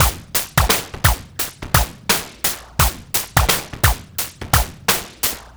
Index of /90_sSampleCDs/Best Service ProSamples vol.24 - Breakbeat [AKAI] 1CD/Partition A/TRIBEVIBE086